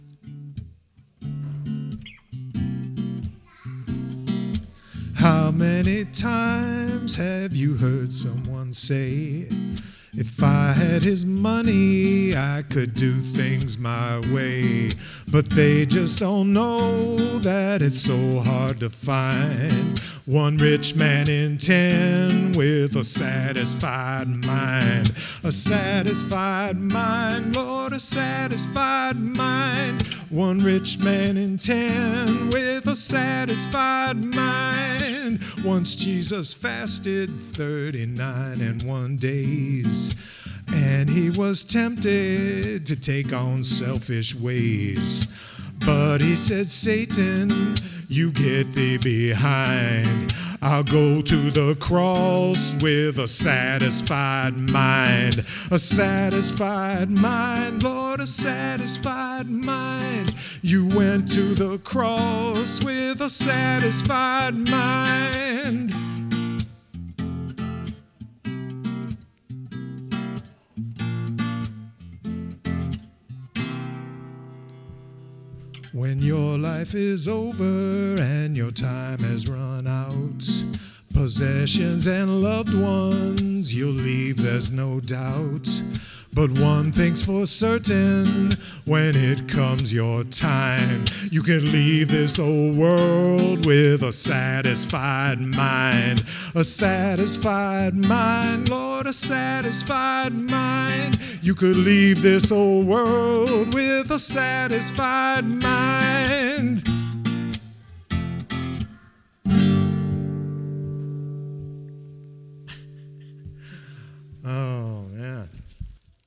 Below are 2 songs recorded live during the concert: